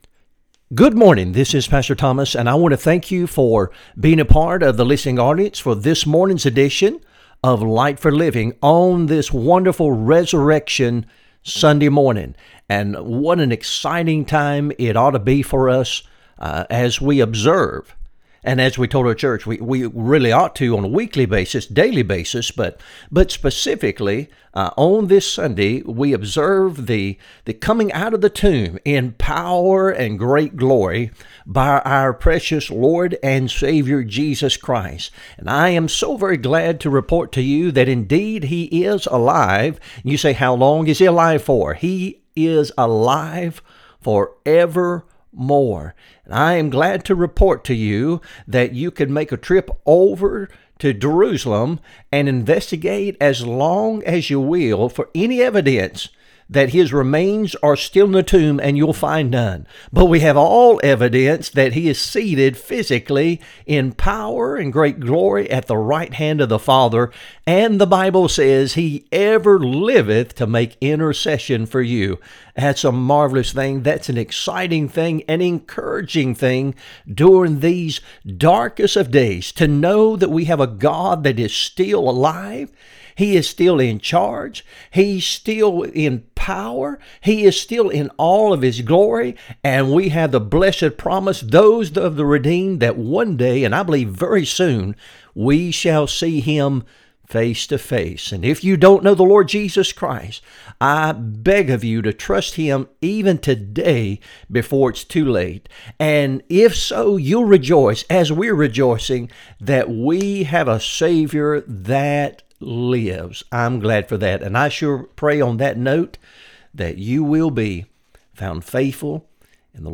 Sermons | True Light Baptist Church of Alton, Virginia